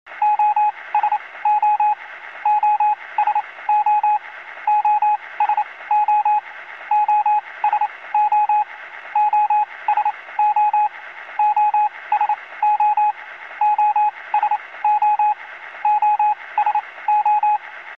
Звуки радио, помех
Азбука Морзе как принимать сигнал SOS